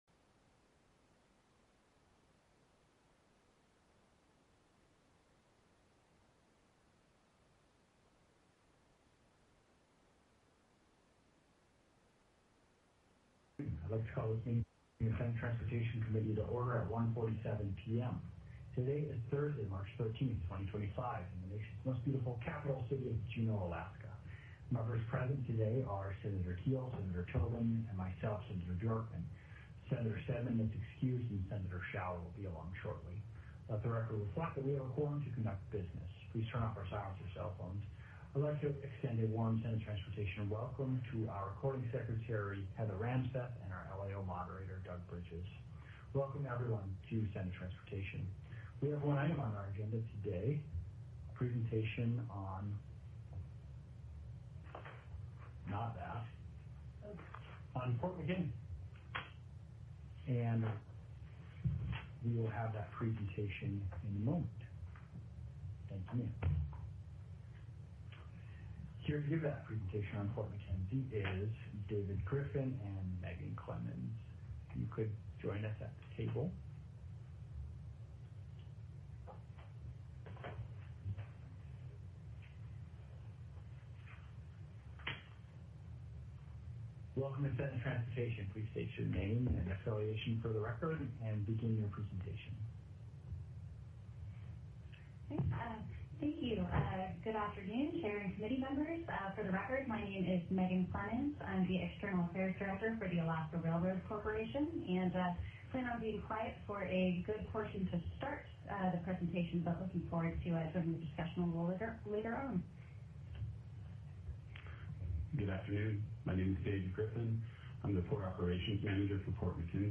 The audio recordings are captured by our records offices as the official record of the meeting and will have more accurate timestamps.
Presentation(s): Port Mackenzie: Multimodal Infrastructure Development